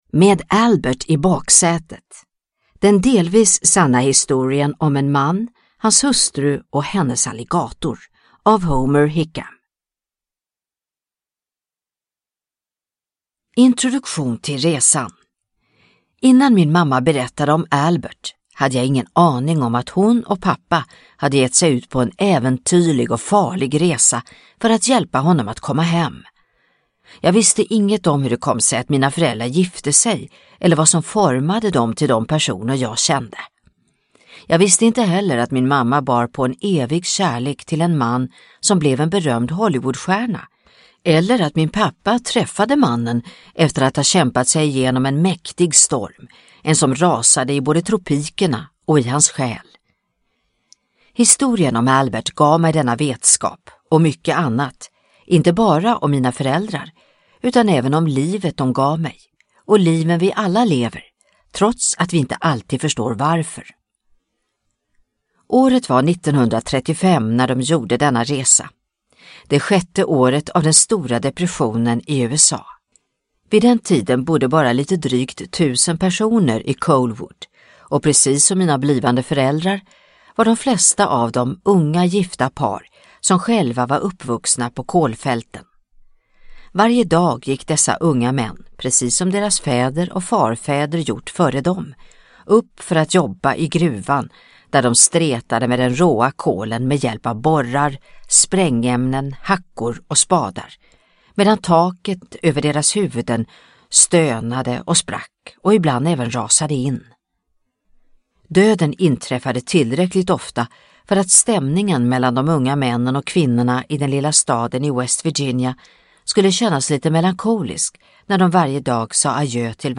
Med Albert i baksätet – Ljudbok – Laddas ner